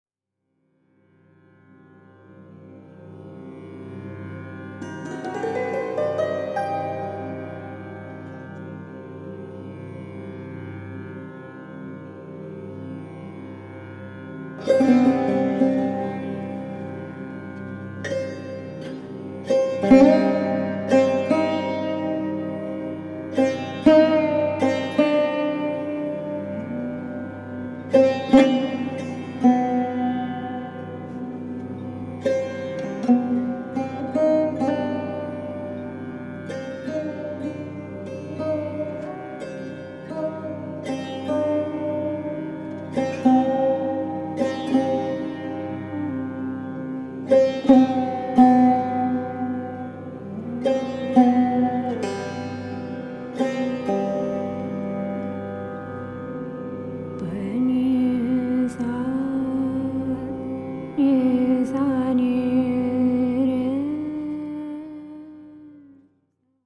Genre: World Fusion.
sarod
bansuri
vocals